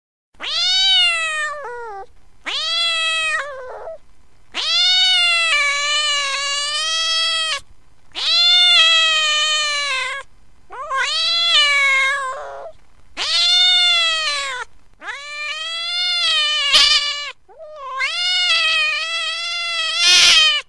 Verärgerte Katze klingelton kostenlos
Kategorien: Tierstimmen
Verärgerte-Katze.mp3